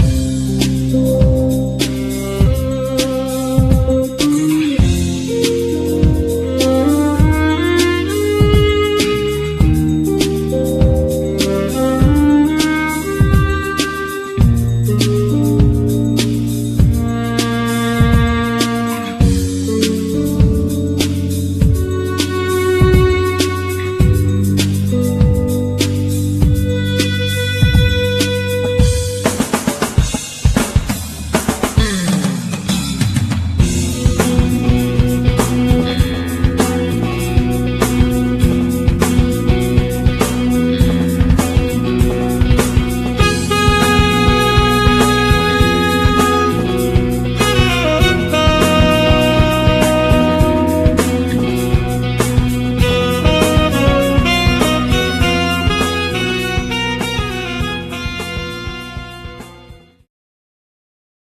skrzypce
saksofon tenorowy, klarnet
gitara akustyczna i elektryczna
bębny, perkusja
gitara basowa i elektryczna